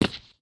8abddf23c7 Divergent / mods / Footsies / gamedata / sounds / material / human / step / test_concrete02.ogg 5.7 KiB (Stored with Git LFS) Raw History
test_concrete02.ogg